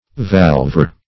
Valvar \Valv"ar\